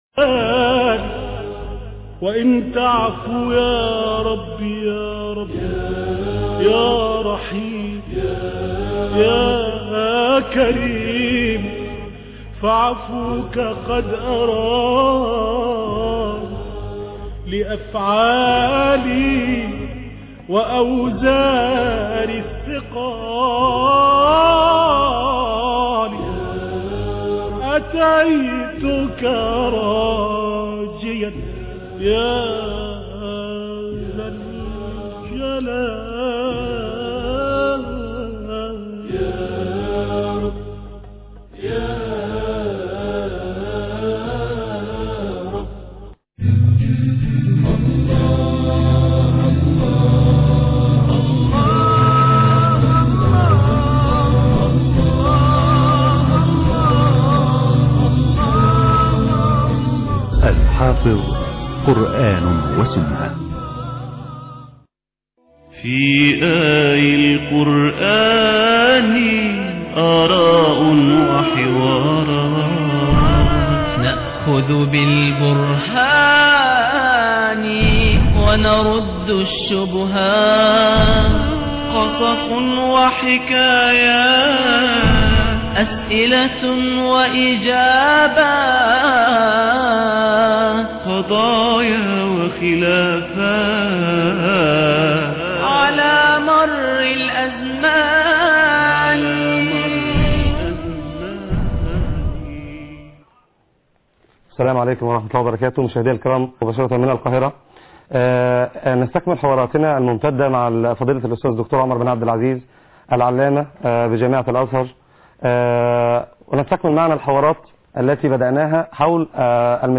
حوارات